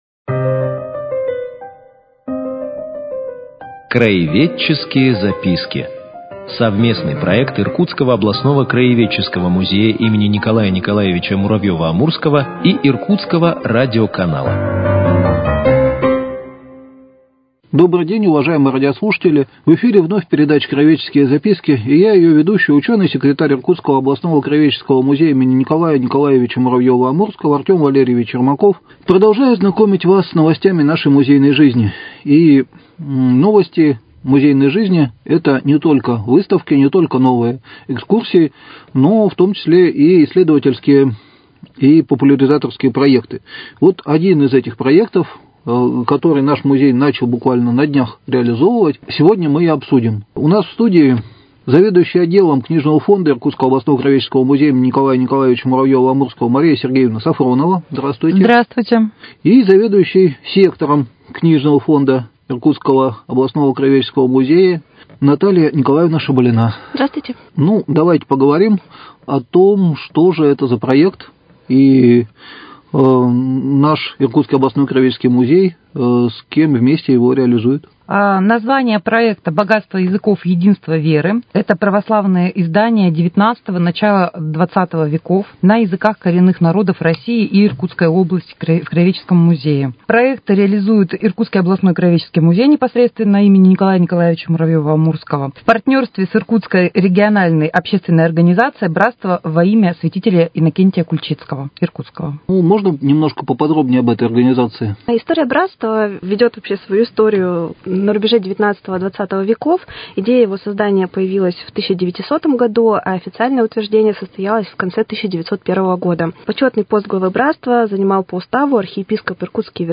Цикл передач – совместный проект Иркутского радиоканала и Иркутского областного краеведческого музея им. Н.Н.Муравьёва - Амурского.